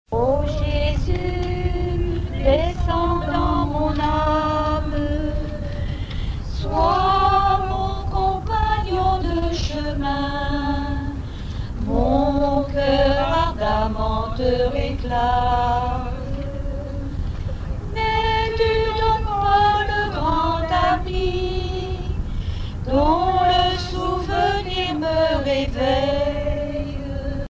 circonstance : dévotion, religion
Genre strophique
Pièce musicale inédite